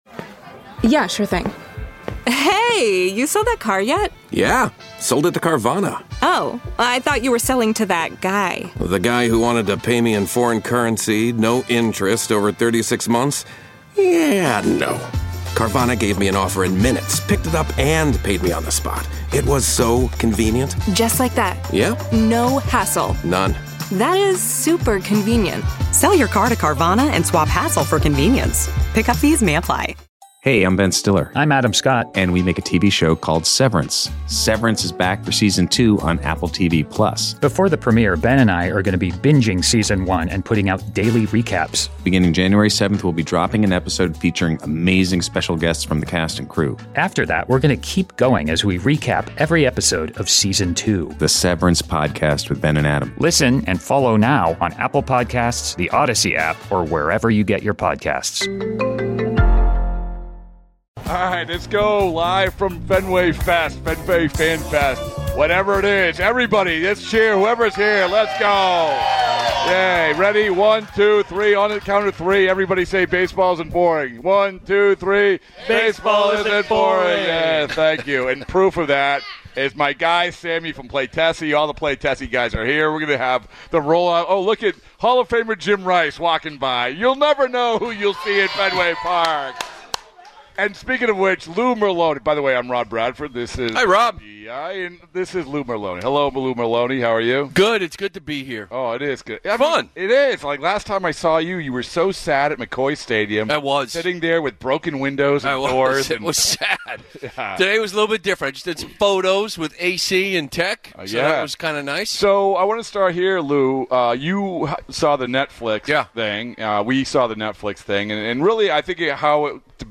Live from Fenway fest